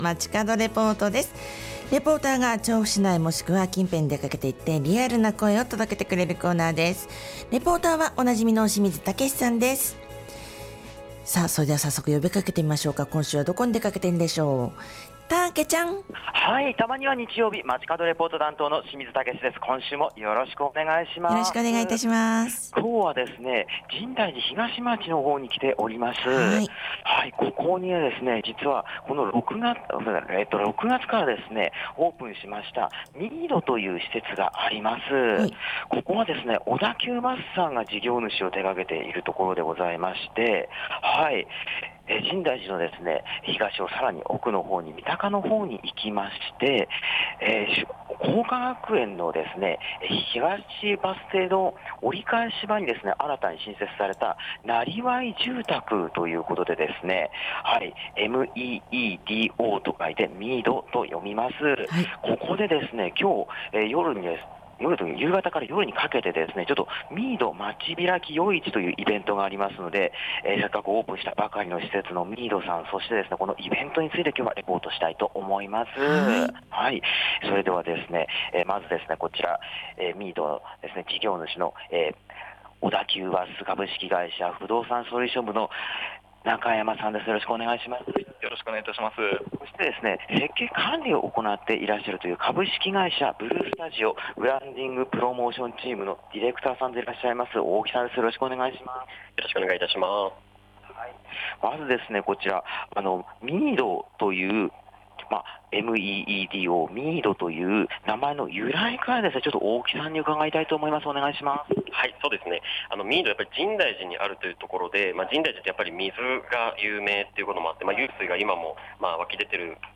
薄曇りの暑い空の下からお届けした街角レポートは、「なりわい住宅meedo（みいど）」で午後15時から行われる「みいど まちびらき 夜市」のレポートです！！